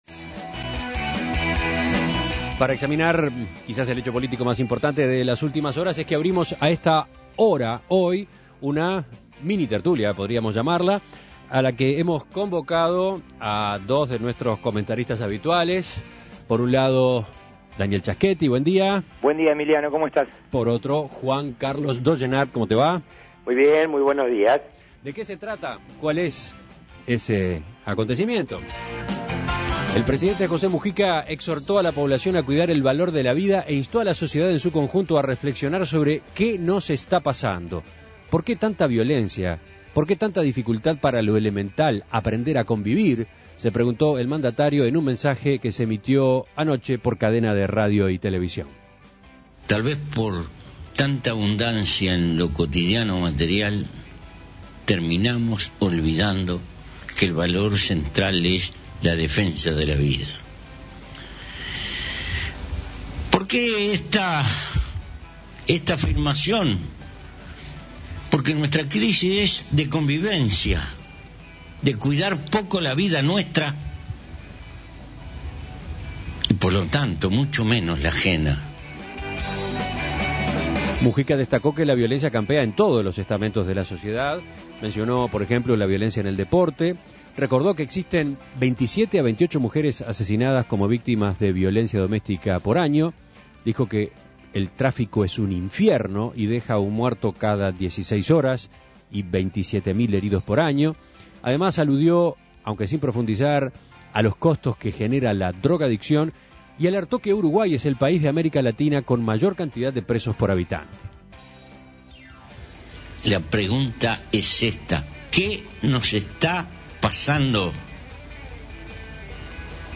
Minitertulia Especial sobre las expresiones de Mujica en cadena nacional (audio)